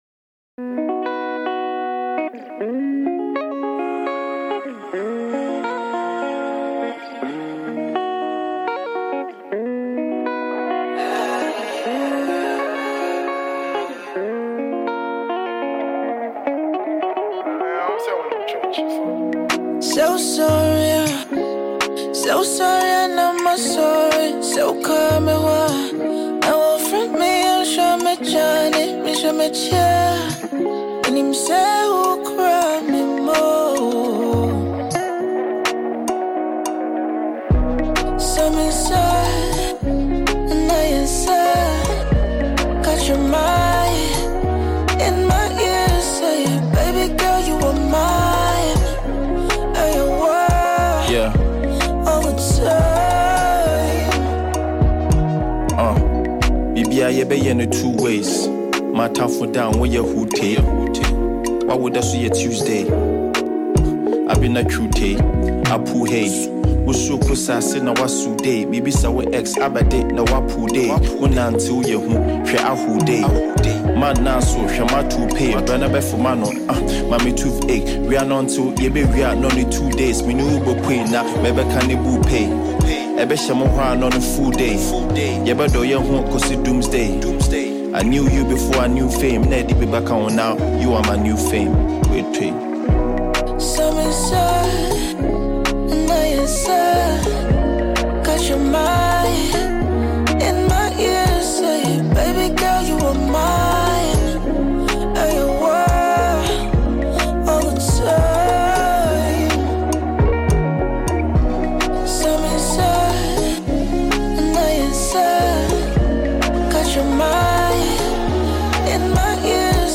Ghanaian rapper